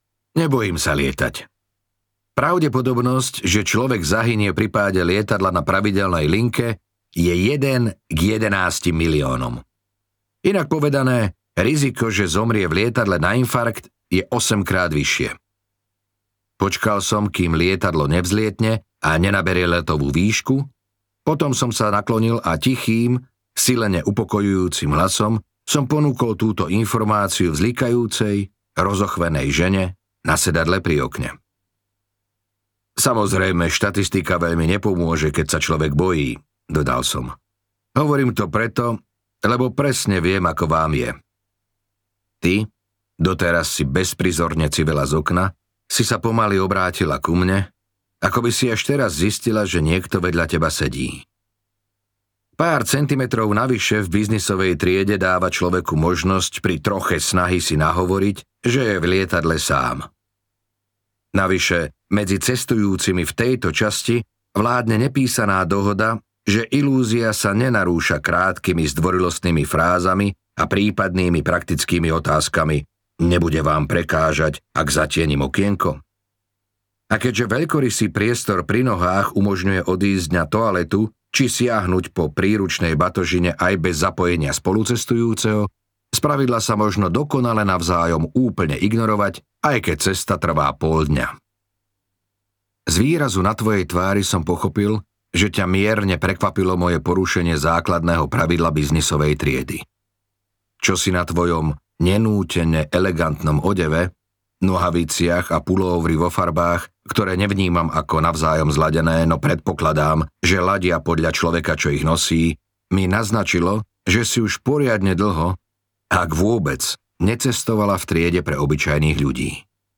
Žiarlivec a iné príbehy audiokniha
Ukázka z knihy